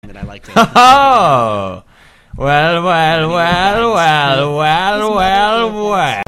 Play, download and share Pewdiepie original sound button!!!!